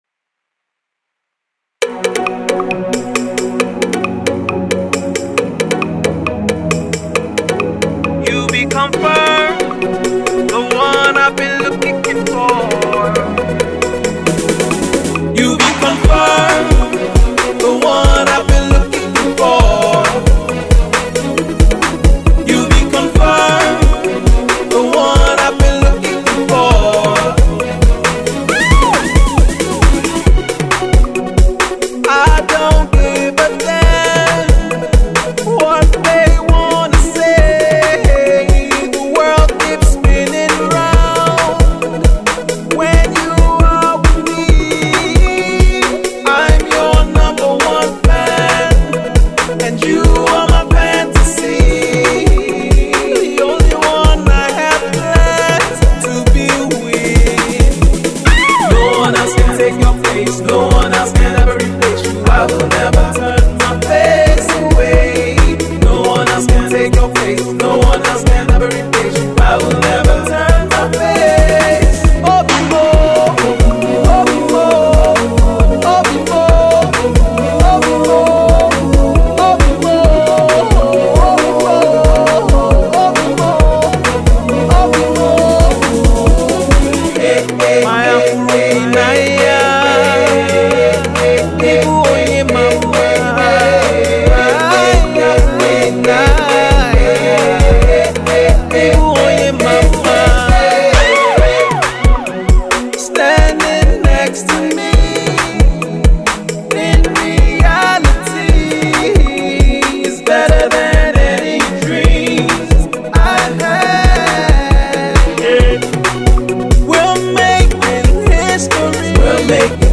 Folk-tinged Africana Pop